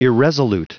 Prononciation du mot irresolute en anglais (fichier audio)